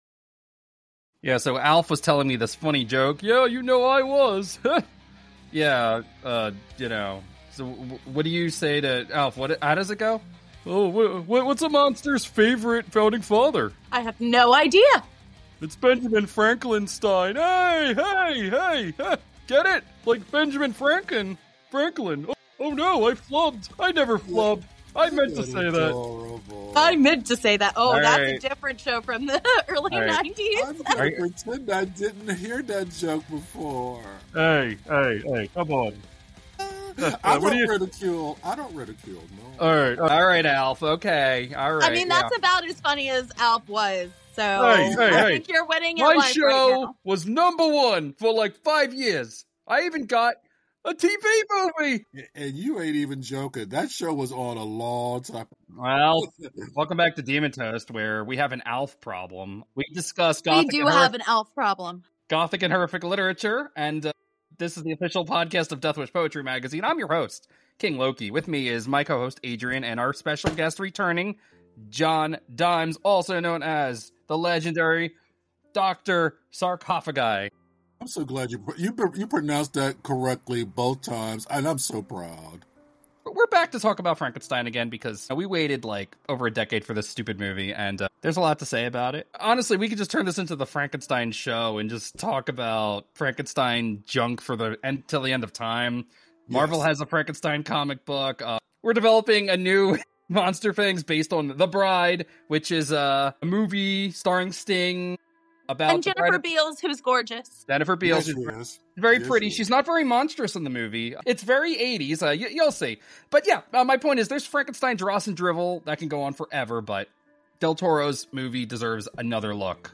A richly textured conversation worthy of the Demon Toast flame.